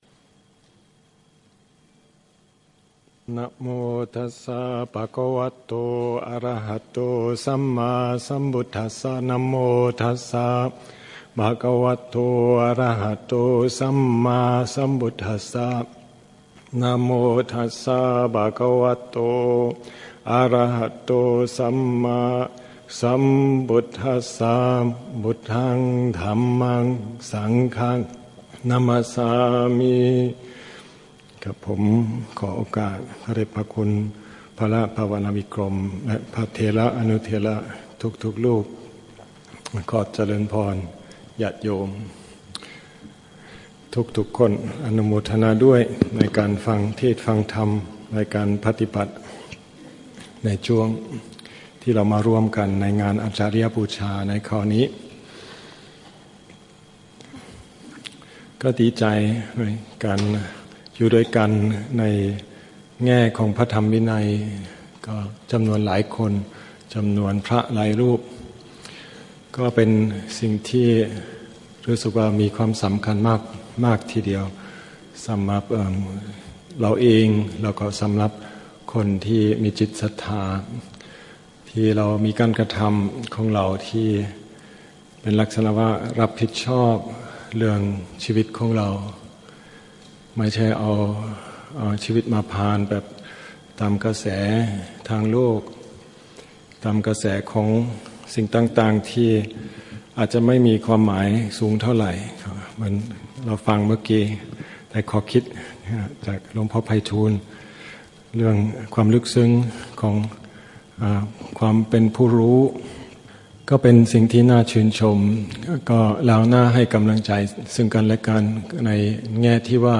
ธรรมเทศนาภาษาไทย Dhamma Talks given at Wat Pah Nanachat in Thai Language